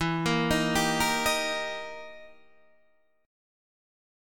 E Minor Major 7th Flat 5th